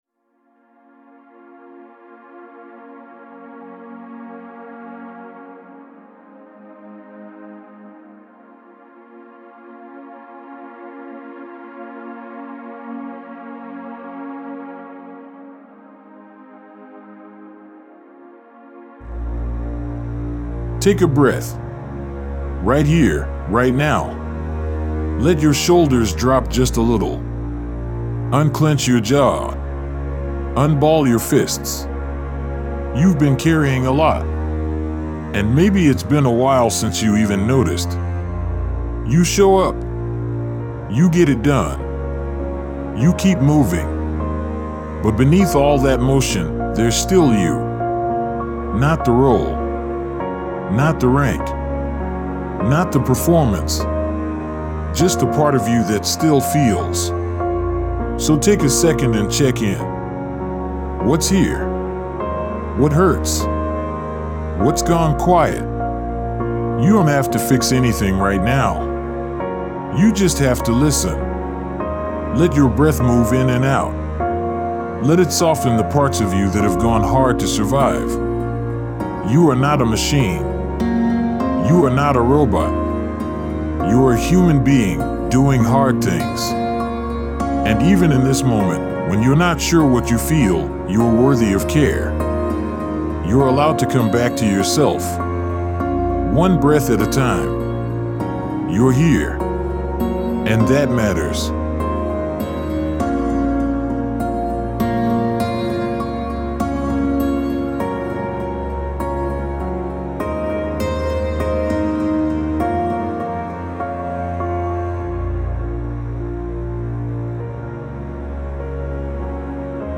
Depression-1-guided-meditation.wav